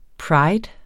Udtale [ ˈpɹɑjd ]